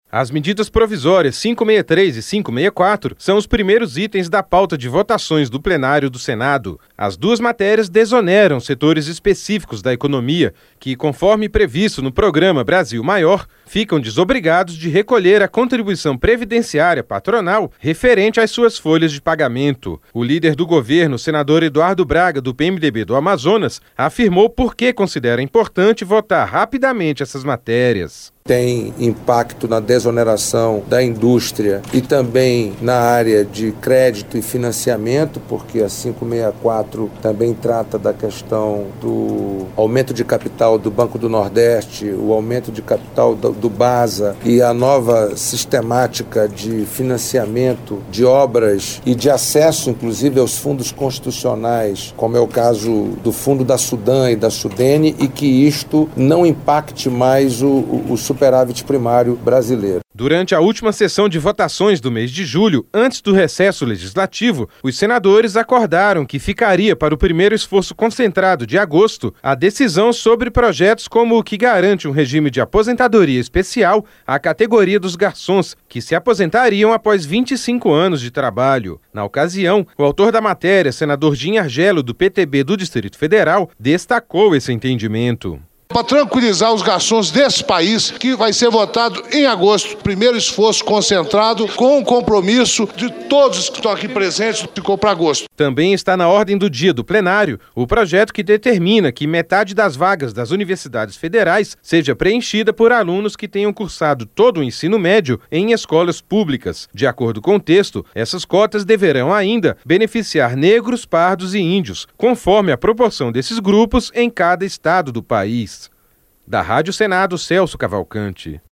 Senador Eduardo Braga